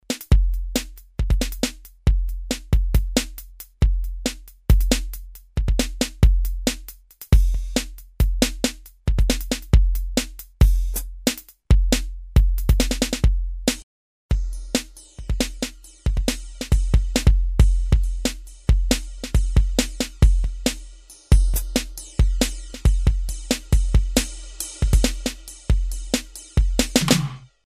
Synthesis: PCM rompler
demo drum pattern